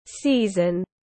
Mùa tiếng anh gọi là season, phiên âm tiếng anh đọc là /ˈsiː.zən/